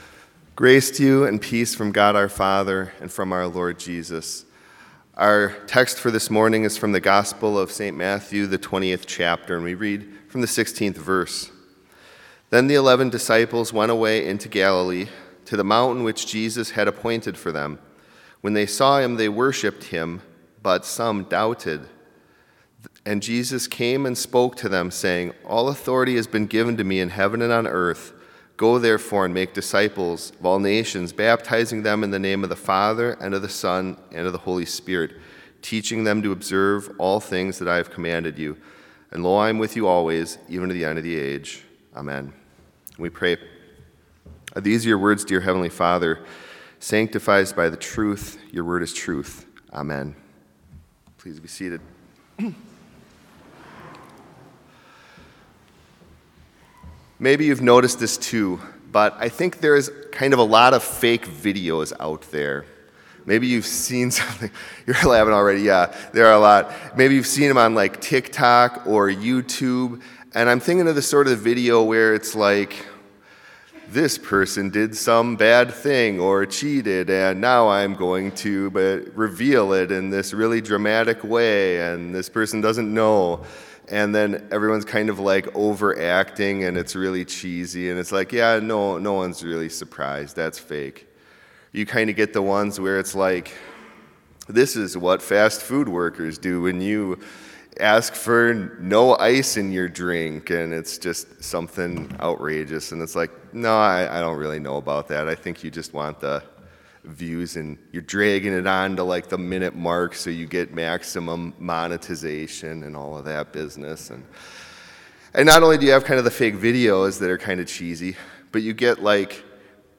Complete service audio for Chapel - January 27, 2022
Complete Service